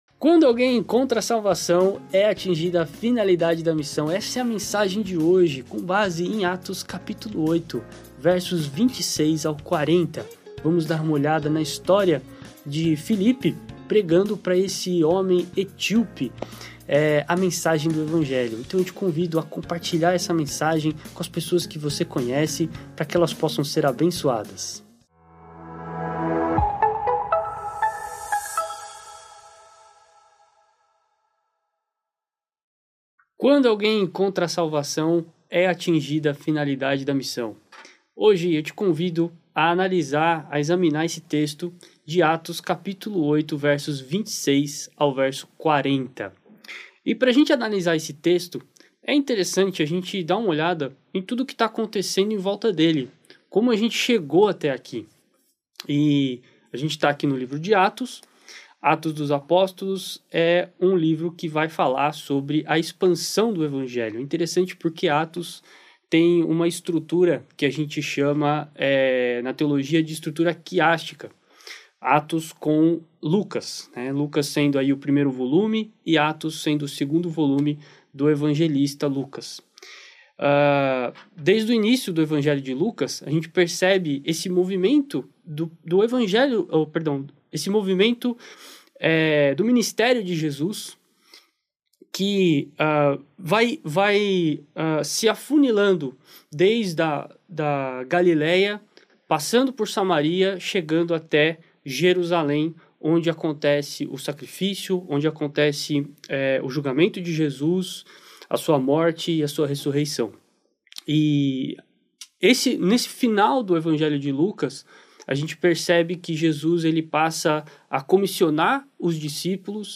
Igreja Batista Nações Unidas